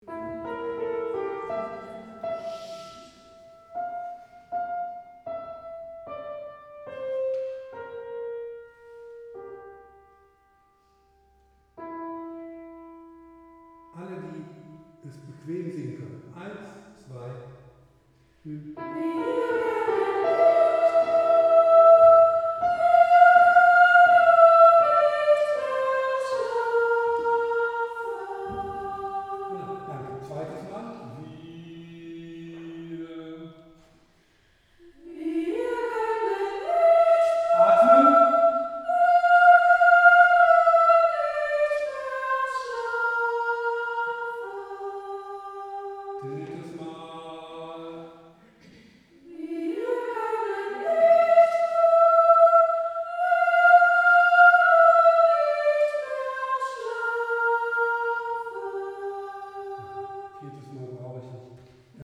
Takt 35 - 42 | Einzelstimmen
Gott in uns! | T 35 | Sopran 1